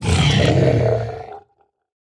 sounds / mob / zoglin / death3.ogg
death3.ogg